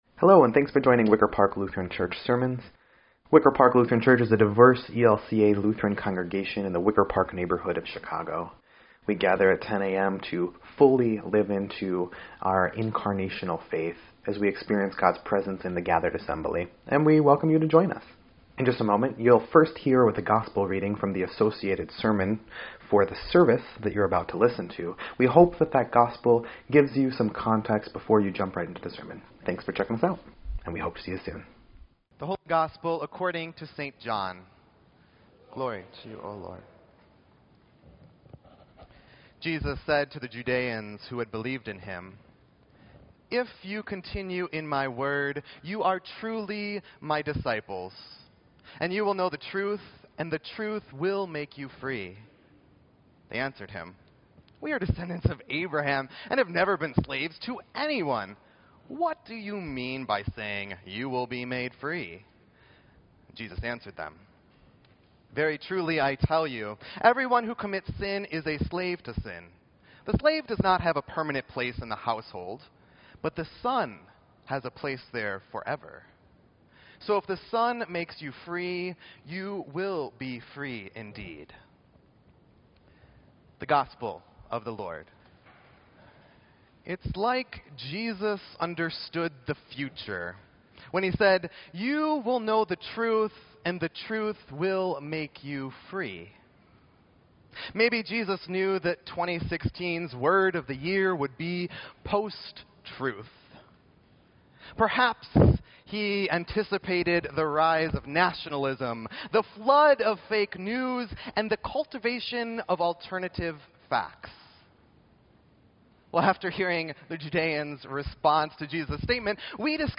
Sermon_10_29_17_EDIT.mp3